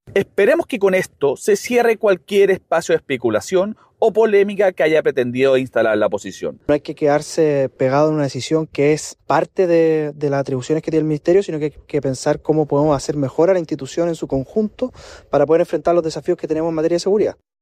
En esa línea, los diputados de la UDI, Eduardo Cretton y Jaime Coloma, llamaron al mundo político a dar vuelta la página y concentrarse en impulsar medidas que fortalezcan el trabajo del ministerio y de las policías.